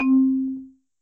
Original 4i Sound Processor xylophone sample, extracted from PDP-11 floppy disk.